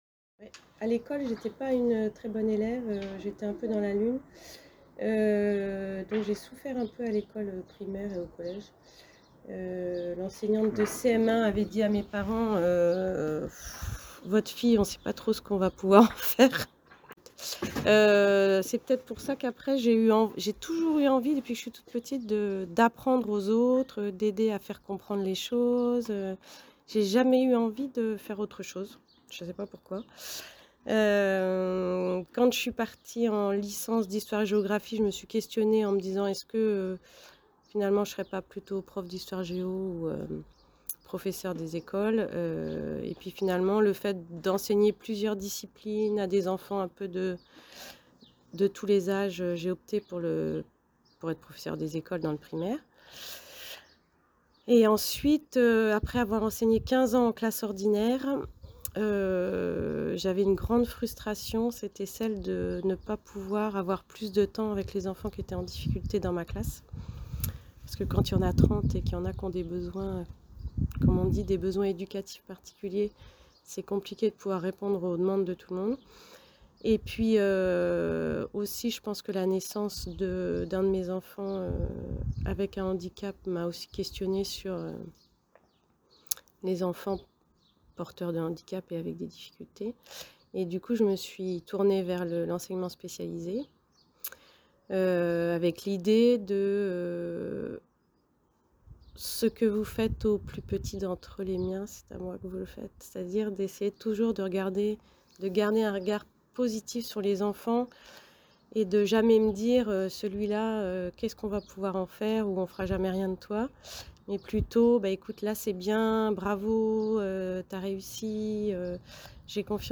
Voici son témoignage.